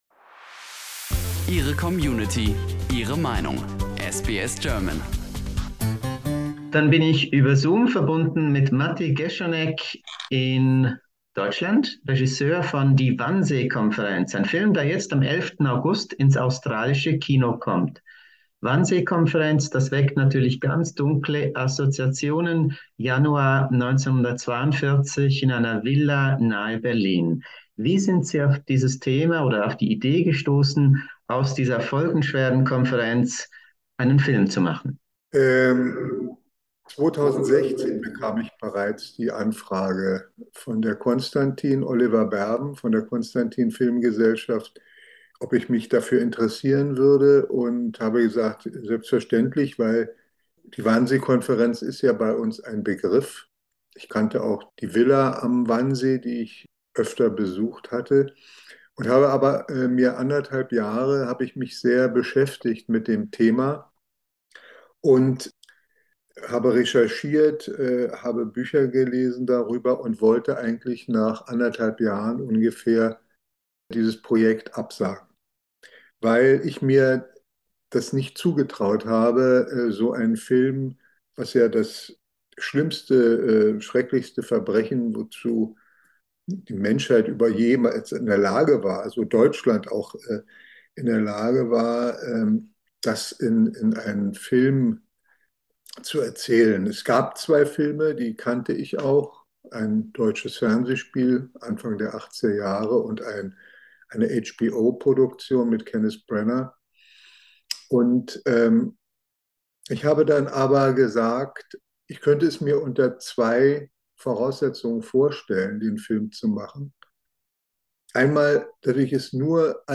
A film about the Holocaust, sober, without music, without sentimentality: This is "Die Wannseekonferenz" ("The Conference" in Australia). An interview with German director Matti Geschonneck.